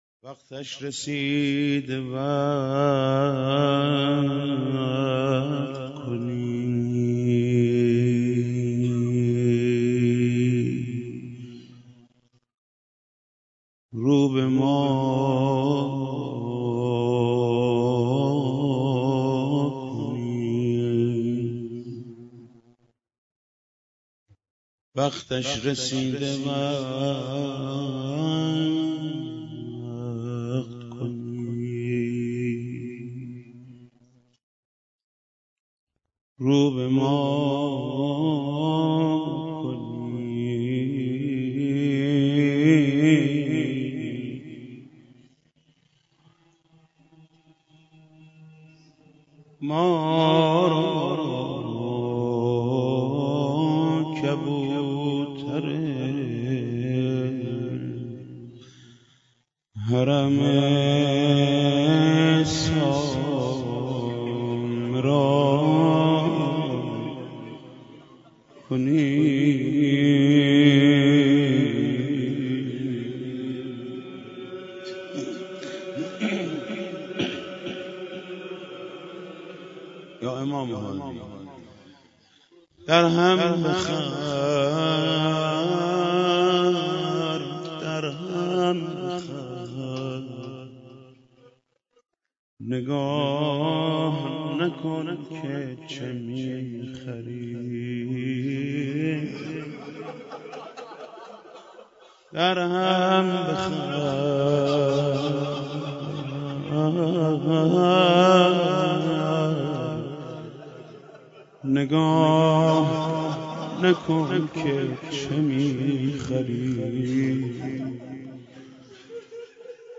ویژه‌نامه شهادت امام هادی علیه السلام شامل زندگی‌نامه، زیارت‌نامه، تصاویر مزار متبرکه، اشعار و گلچین مداحی به‌مناسبت شهادت آن حضرت منتشر می‌شود.